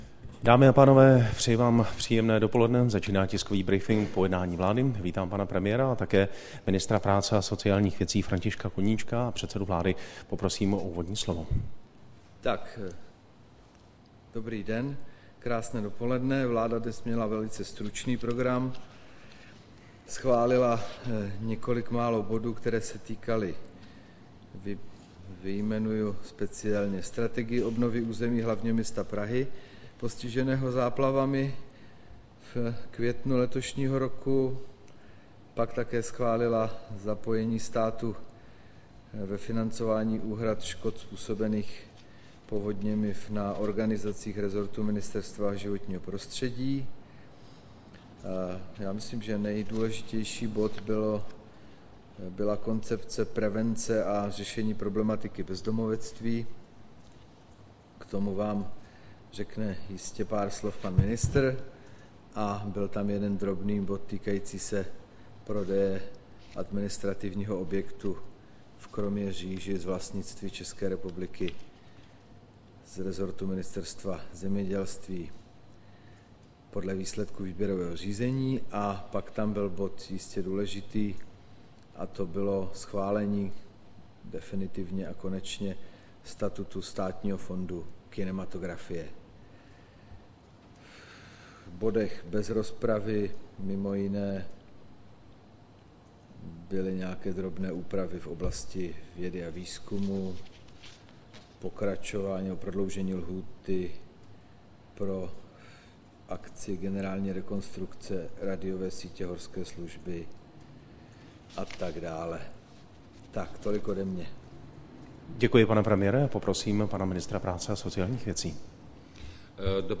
Tisková konference po jednání vlády, 28. srpna 2013
Předseda vlády Jiří Rusnok spolu s ministrem práce a sociálních věcí Františkem Koníčkem představili výsledky jednání vlády. Jedním z hlavních bodů byla Koncepce prevence a řešení problematiky bezdomovectví v ČR do roku 2020 a dále body související s financováním škod způsobených povodněmi v letošním roce. Premiér pak na novinářský dotaz odpovídal k problematice týkající Sýrie a ministr k průkazkám TP resp. ZTP a ZTP/P.